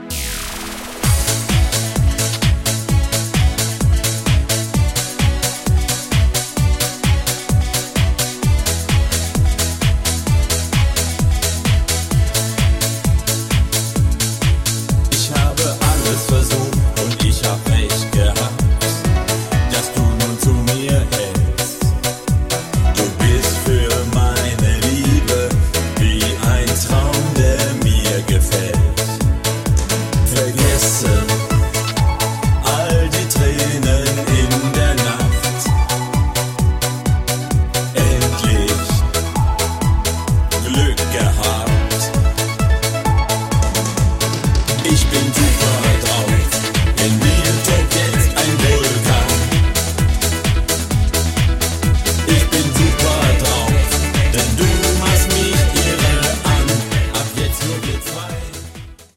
--- Schlager ---